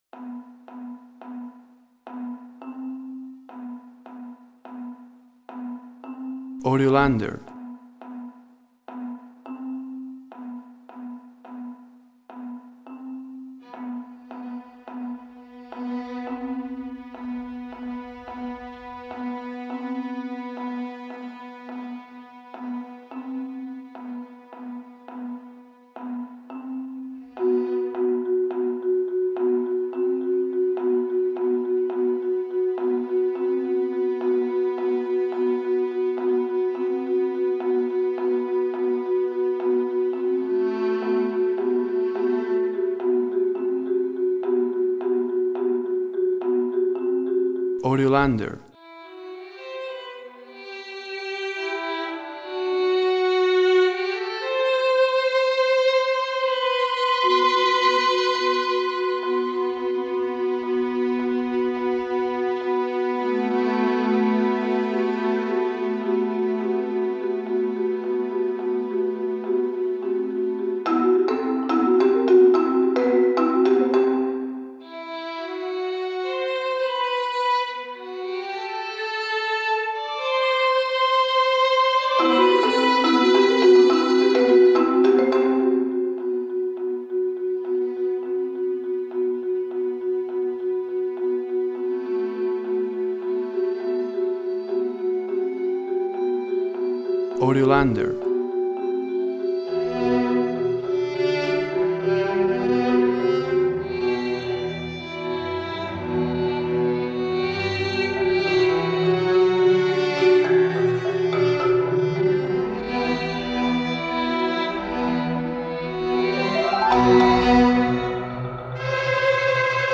Complex rhythms, some dissonance.